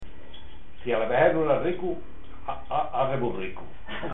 Materia / geográfico / evento: Refranes y proverbios Icono con lupa
Secciones - Biblioteca de Voces - Cultura oral